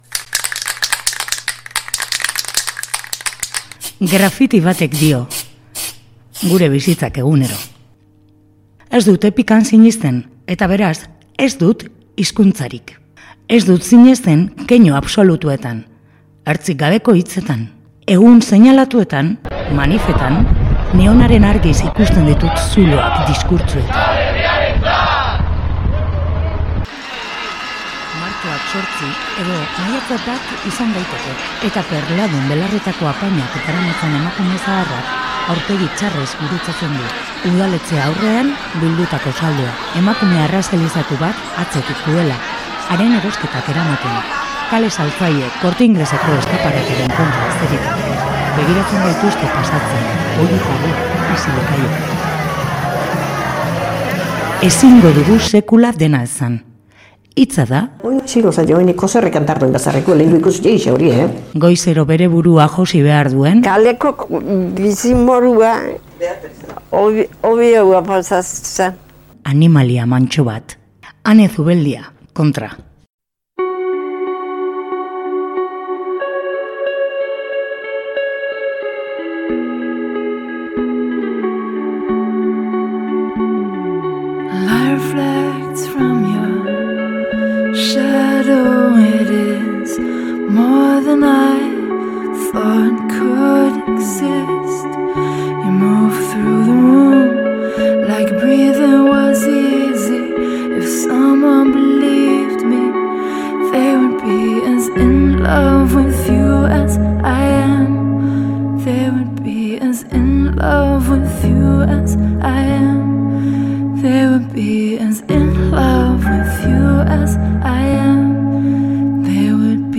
Gaur bidaia txiki batean murgilduko gara hitzen eta musikaren bidez.